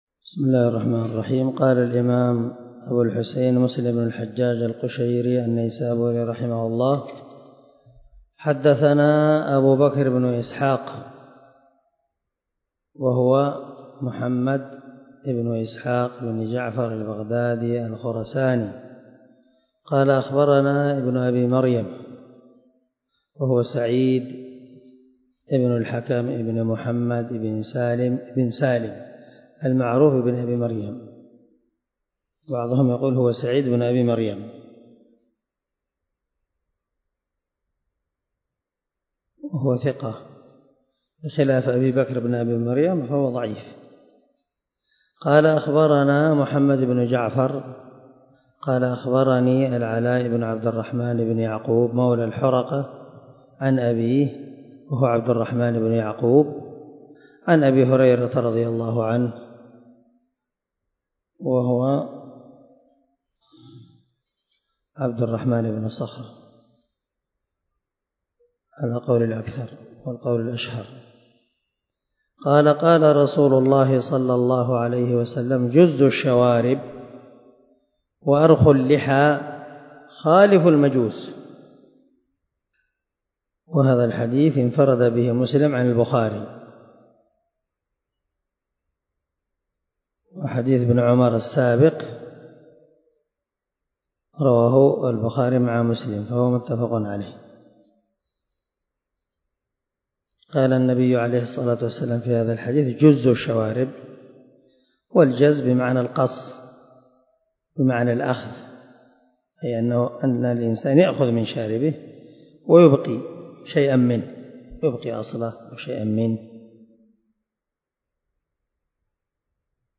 195الدرس 23 من شرح كتاب الطهارة حديث رقم ( 260 ) من صحيح مسلم
دار الحديث- المَحاوِلة- الصبيحة